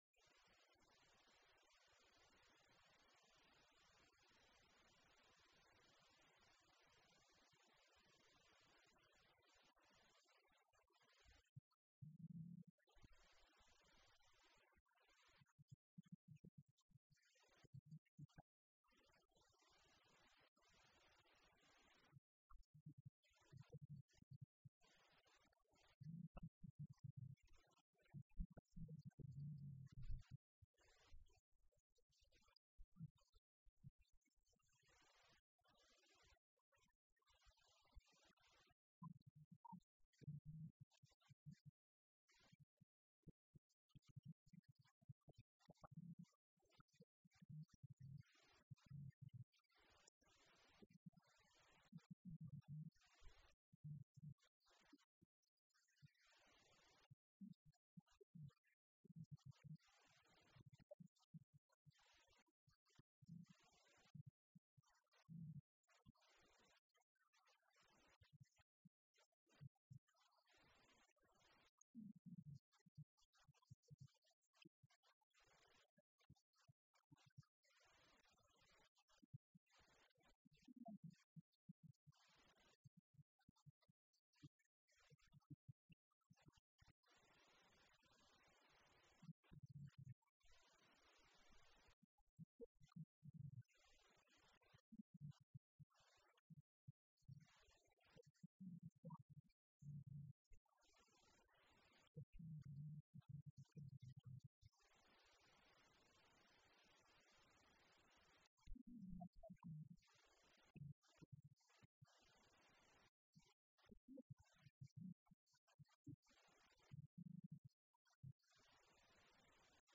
This sermon was given at the Italy 2015 Feast site.